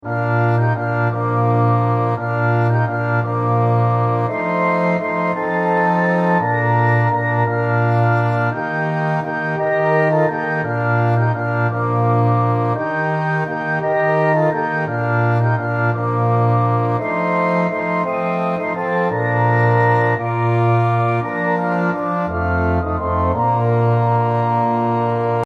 Hymns of praise
Orchestral Version